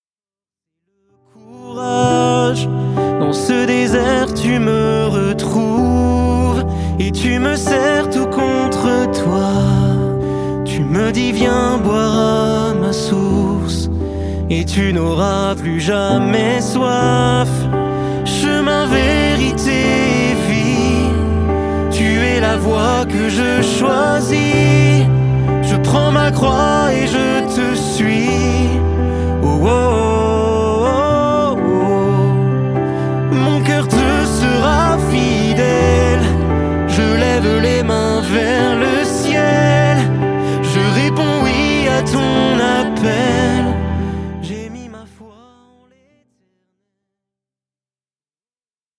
pop louange